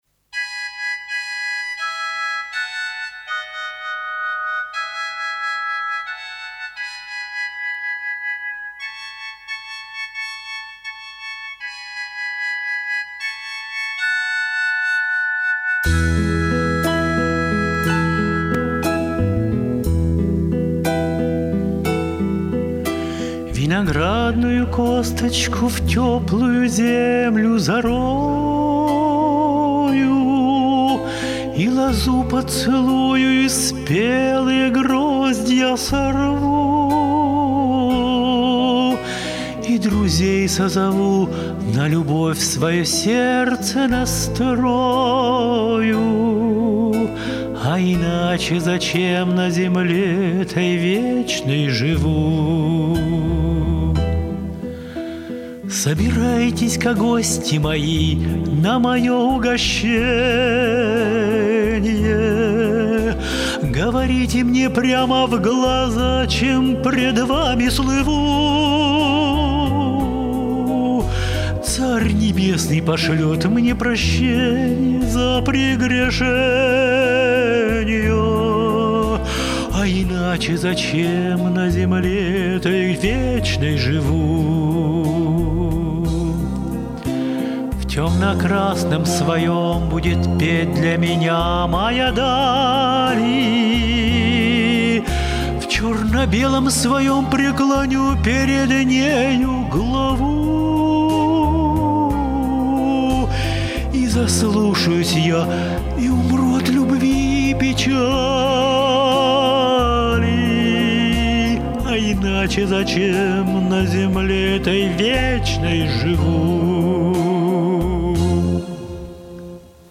Вы хорошо звучите.